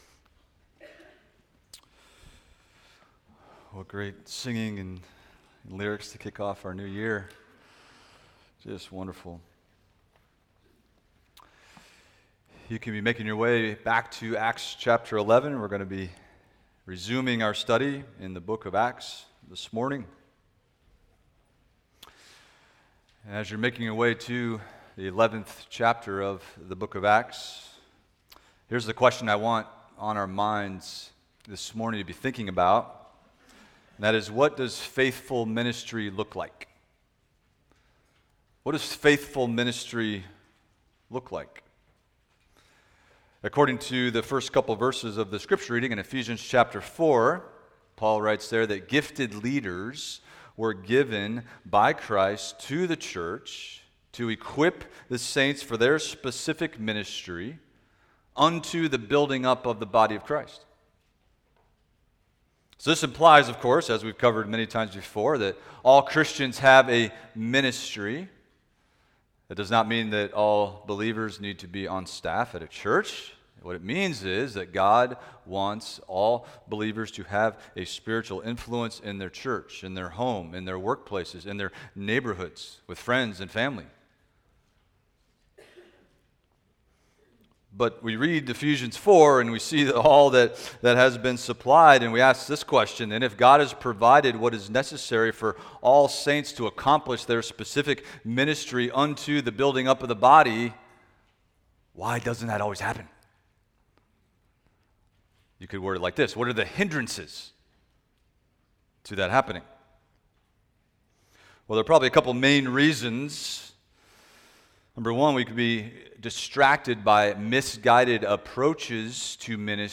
Expository Preaching from First Peter – 1 Peter 1:6-9 Rejoicing Greatly in Hope